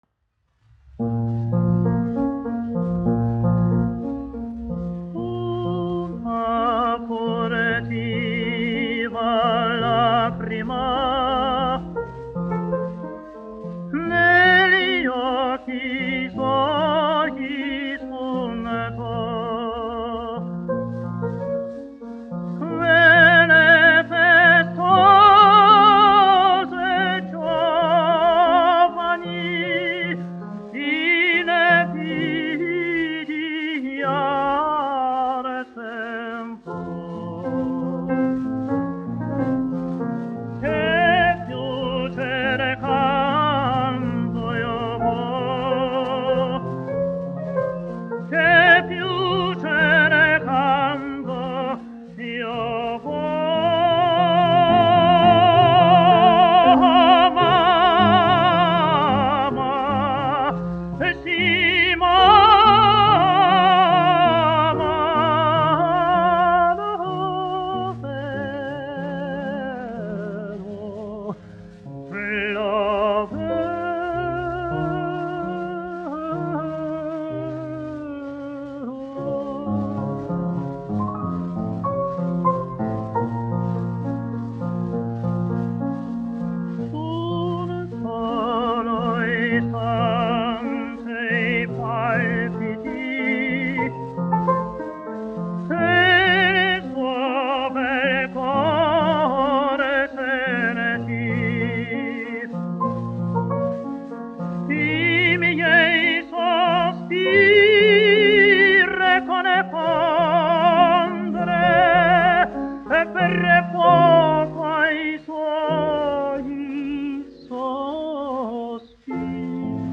1 skpl. : analogs, 78 apgr/min, mono ; 25 cm
Operas--Fragmenti, aranžēti
Skaņuplate